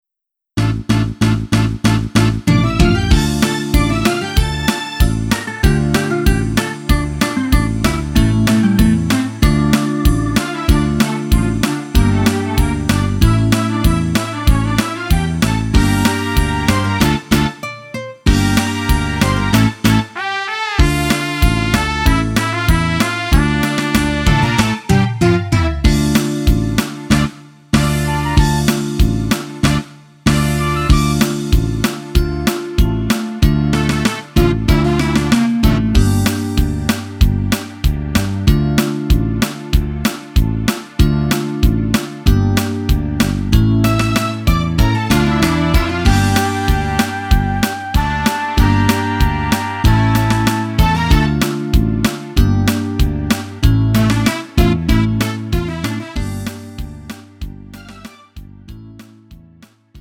음정 -1키 3:05
장르 가요 구분 Lite MR